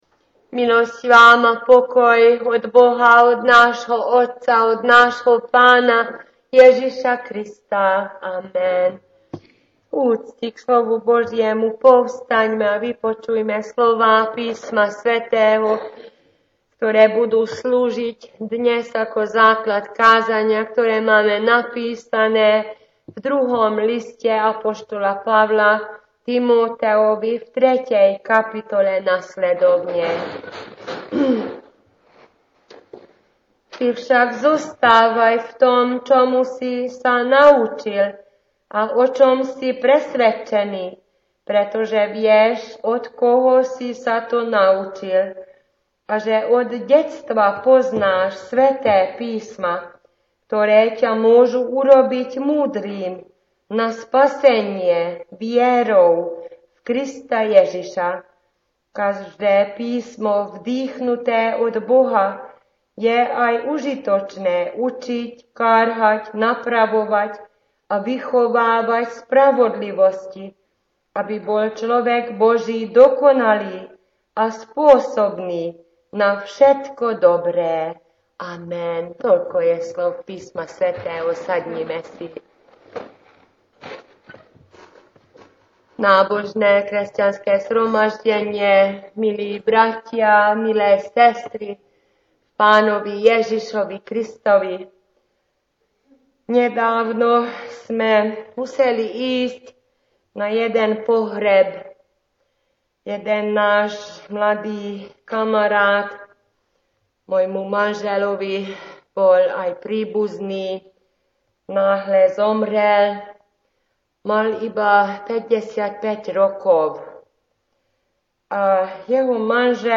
szlovák nyelvű igehirdetése